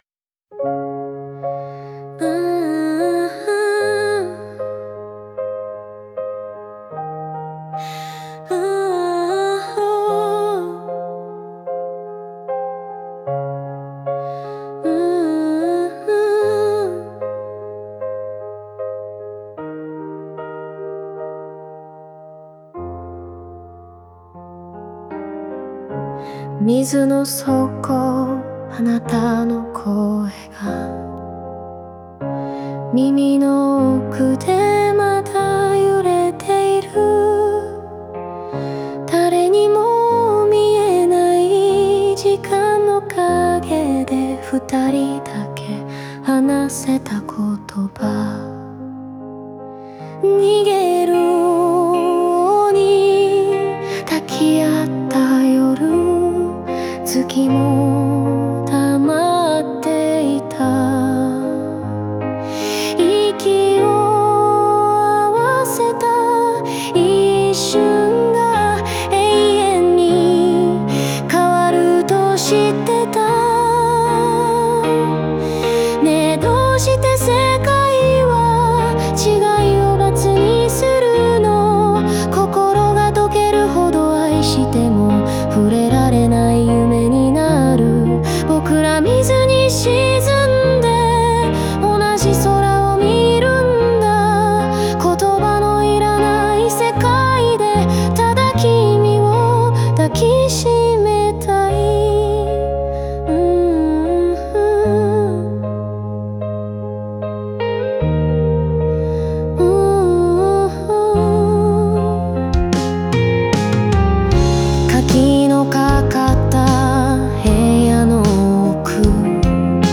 オリジナル曲♪
クライマックスでは「命よりも大切なもの」としての愛を選ぶ決断が、静かで力強く歌い上げられます。